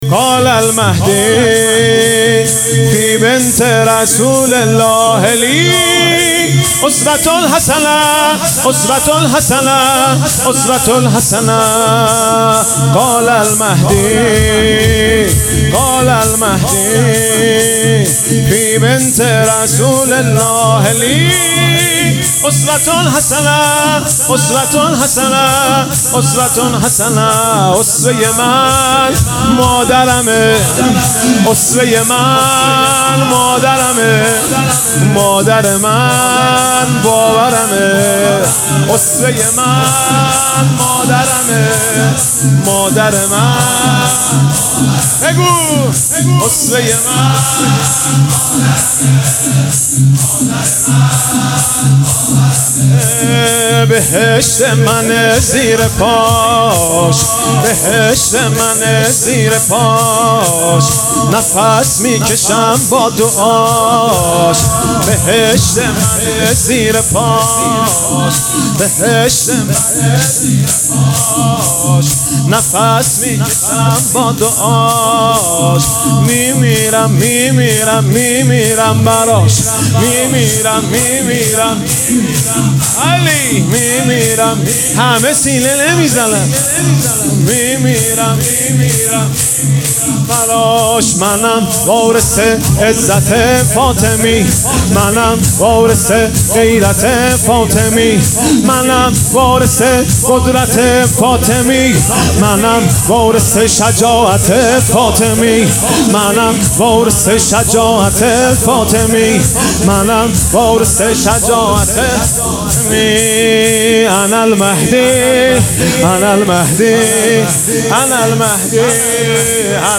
شورـ قال المهدی فی بنتِ رسول الله لی اسوه حسنه ـ مهدی رسولی